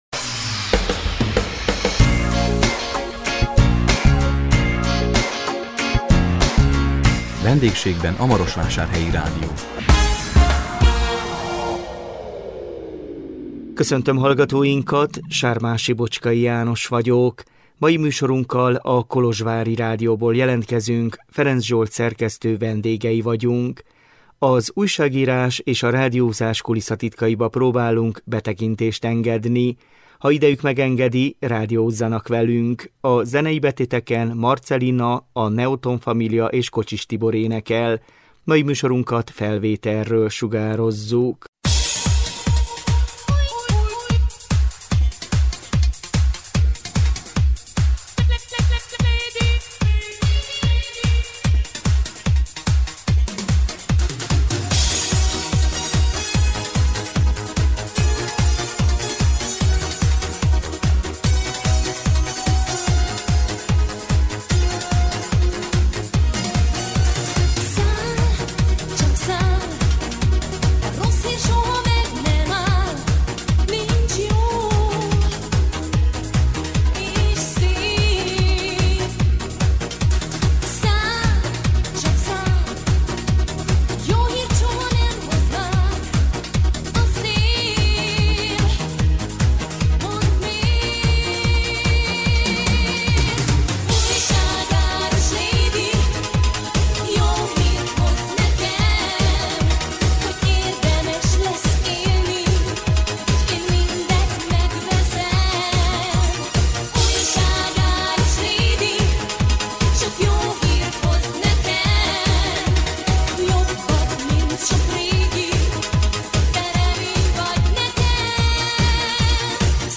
A 2024 október 24-én közvetített VENDÉGSÉGBEN A MAROSVÁSÁRHELYI RÁDIÓ című műsorunkkal a Kolozsvári Rádióból jelentkeztünk